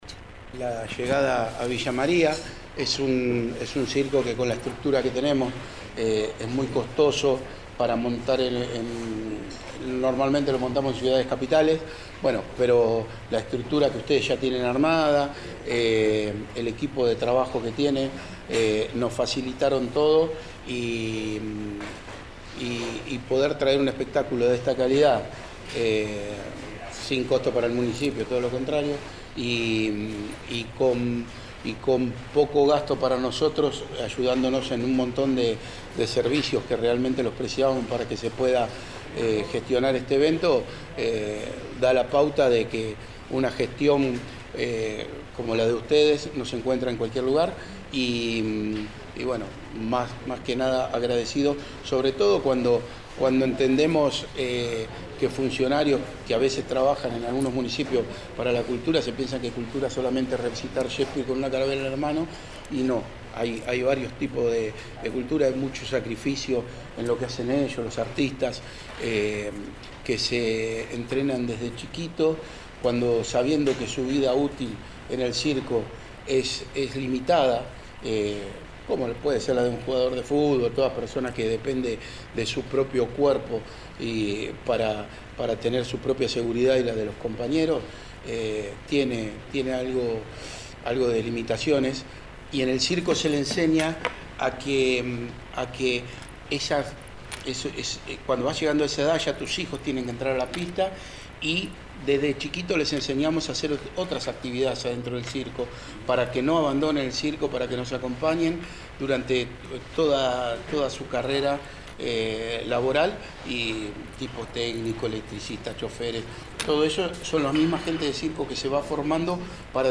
declaró en una conferencia de prensa